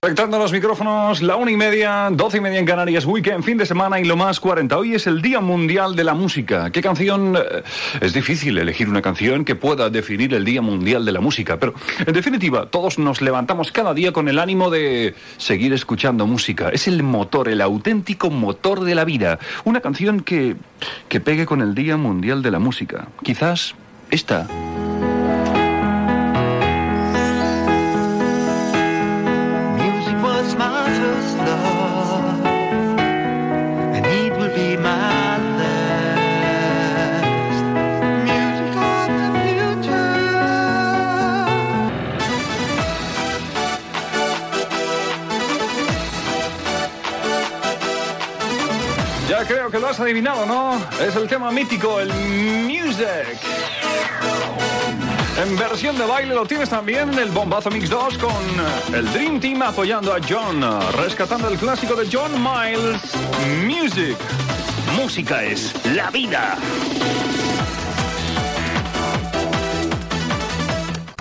Hora, esment al Dia mundial de la música i tema musical
Musical
Martínez, Fernando (Fernandisco)
FM